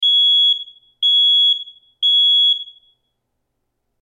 Smoke alarm
BEEP BEEP BEEP BEEP BEEP BEEP
smoke-alarm-made-with-Voicemod-technology.mp3